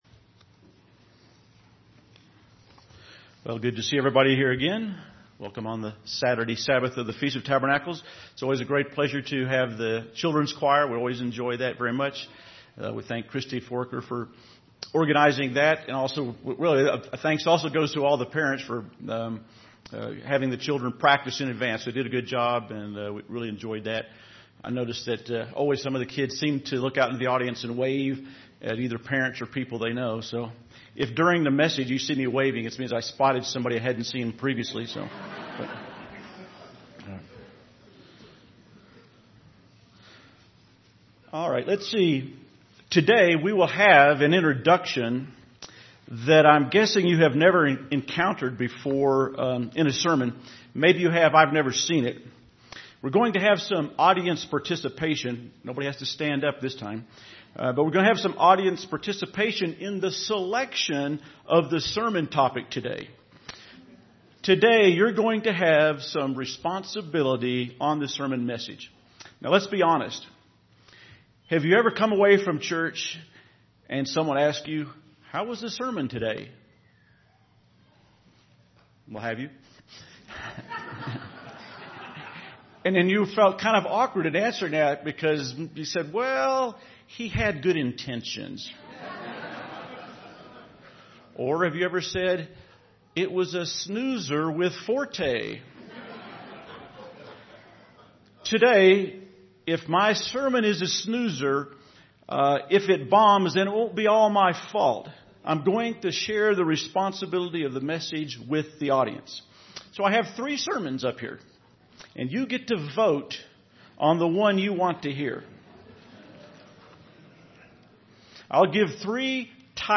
This sermon was given at the Steamboat Springs, Colorado 2014 Feast site.